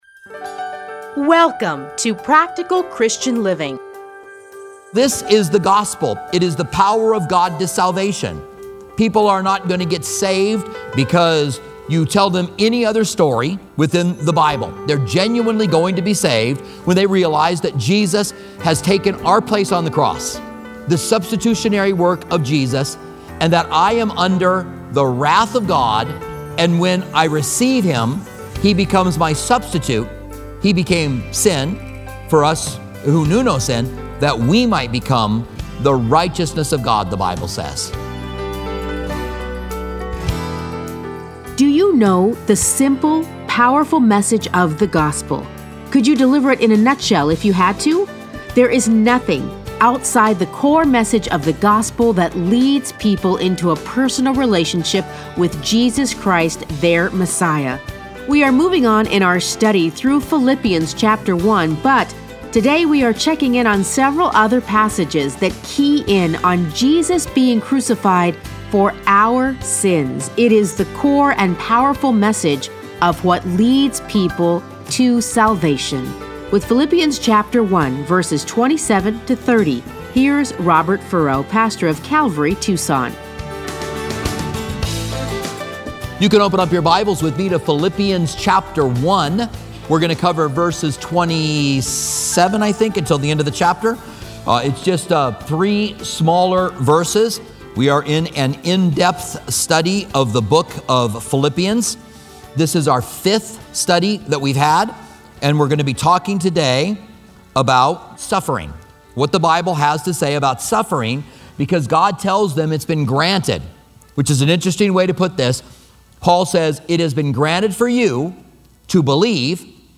Listen to a teaching from Philippians 1:27-30.